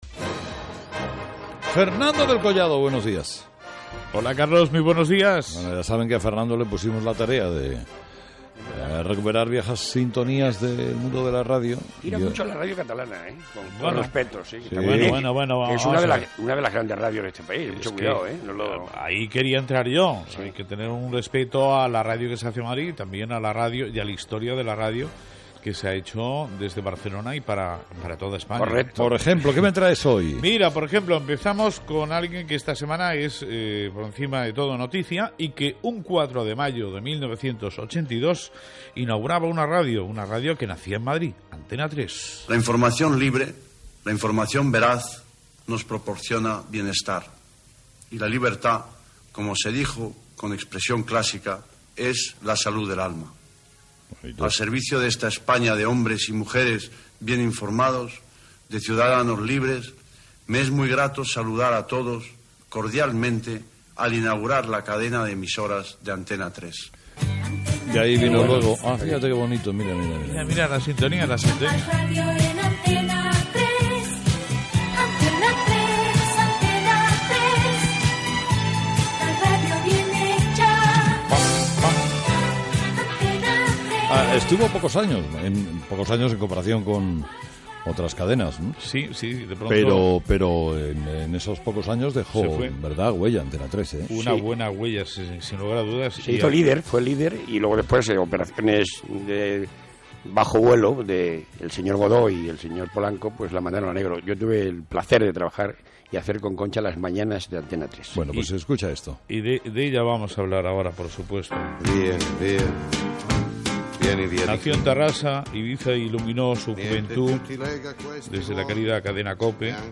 Espai dedicat a enregistraments radiofònics del passat: fragments d'Antena 3, Radio España de Barcelona, Radio Madrid, el programa “Caperucita y el Lobo” de Radio Miramar amb Carlos Herrera i José Manuel Parada Gènere radiofònic Info-entreteniment